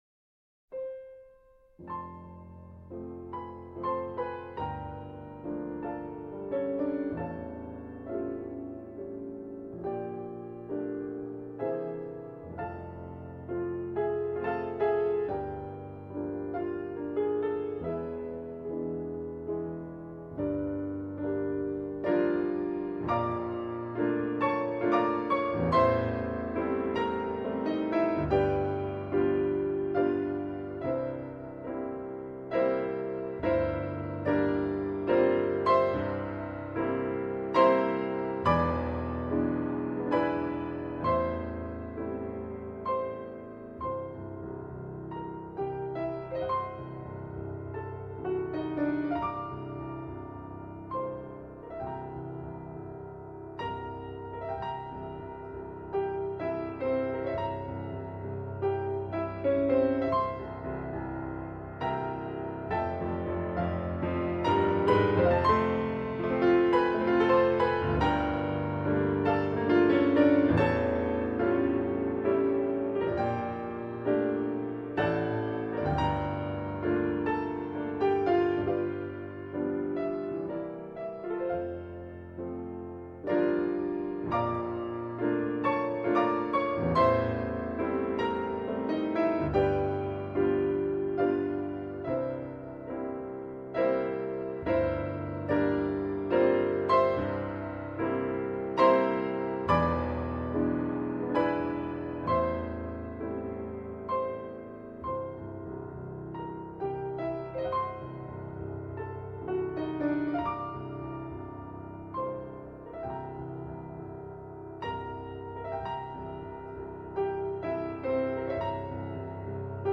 เพลงพระราชนิพนธ์